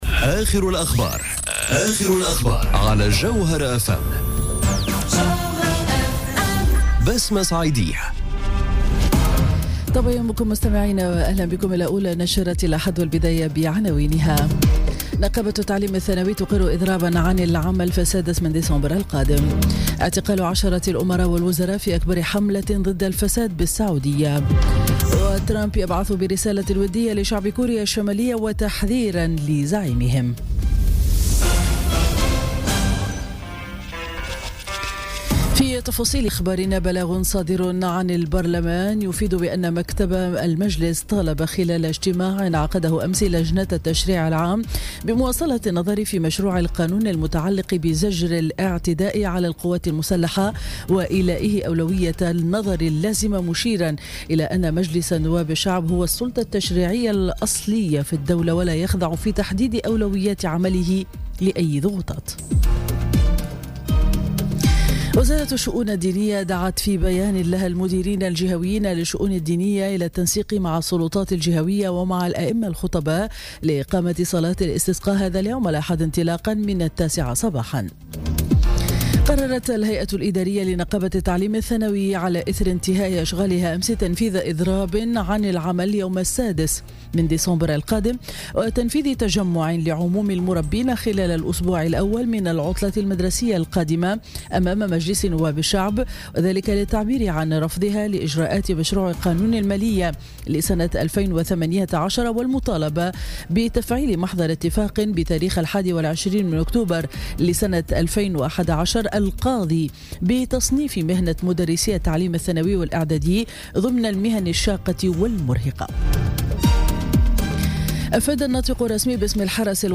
نشرة أخبار السابعة صباحا ليوم الأحد 5 نوفمبر 2017